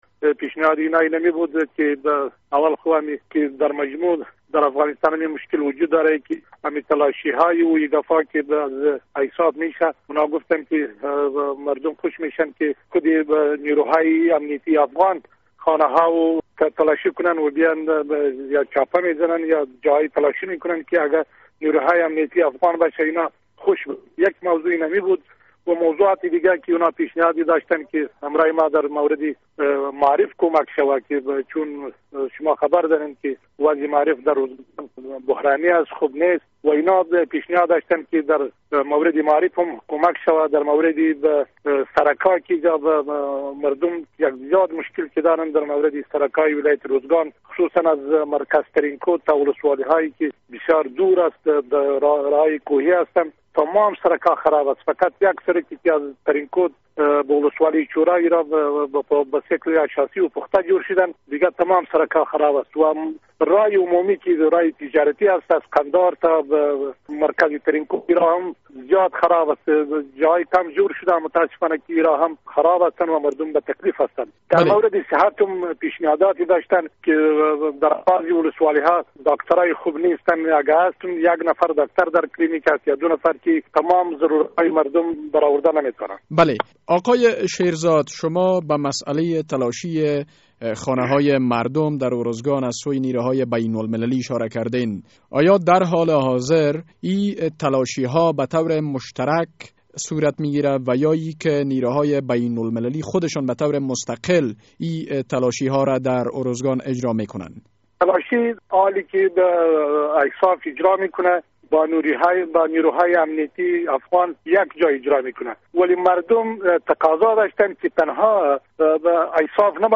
مصاحبه با والی ارزگان در مورد نشست بزرگان قومی و موسفیدان